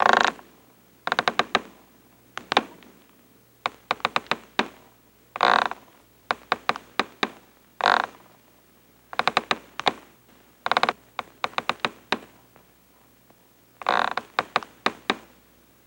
Rope Creaks Medium Fast